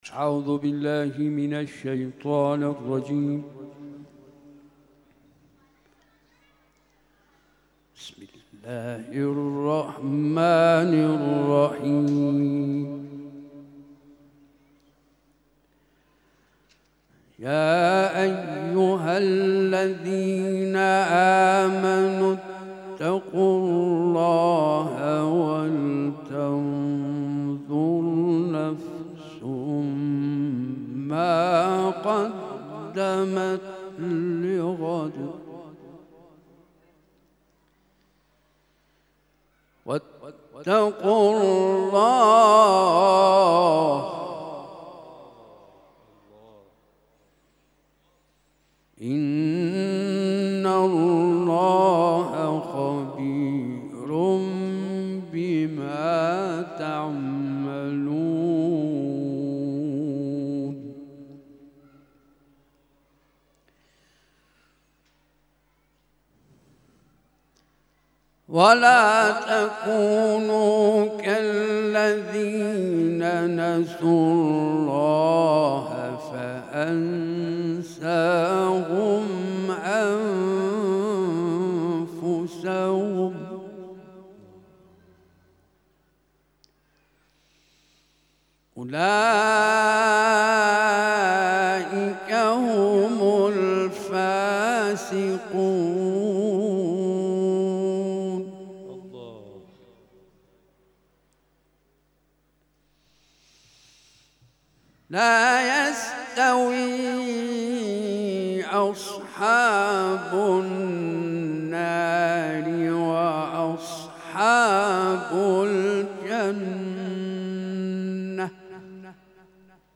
این تلاوت جمعه، 22 مردادماه در مصلای ری و در آستان حضرت عبدالعظیم(ع) اجرا شده است .